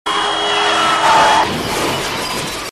LOUD CAR CRASH.mp3
Original creative-commons licensed sounds for DJ's and music producers, recorded with high quality studio microphones.
loud_car_crash_8c9.ogg